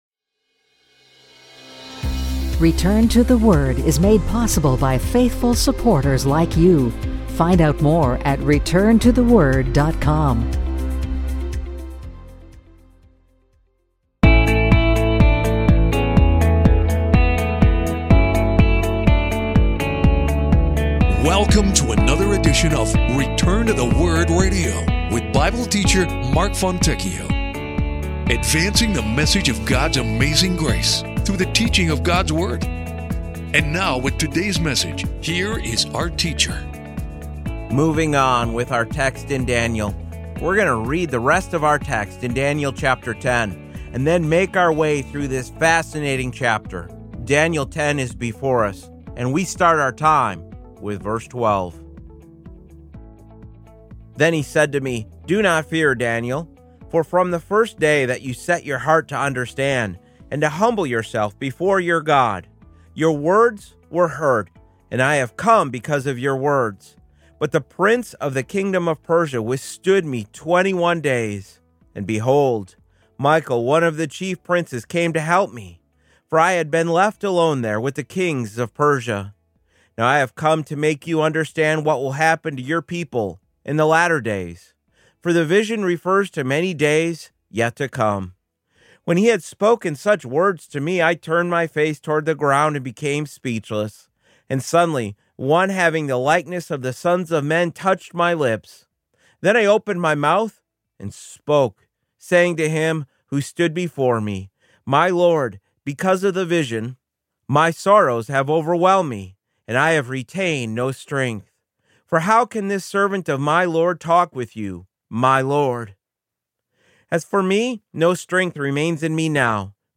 Bible Teacher